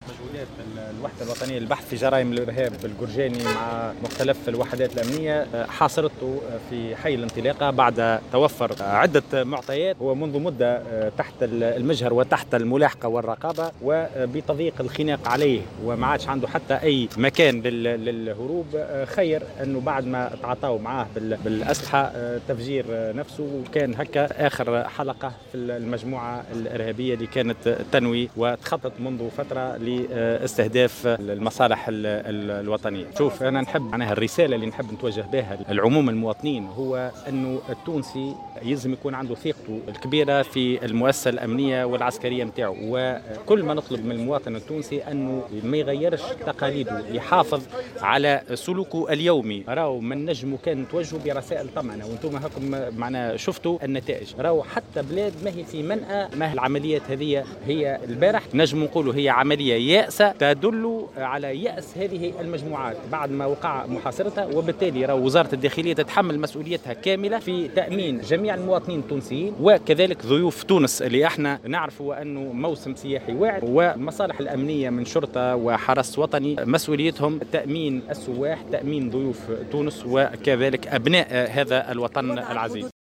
قال وزير الداخلية، هشام فوراتي، في تصريح لمراسل "الجوهرة اف أم" اليوم الأربعاء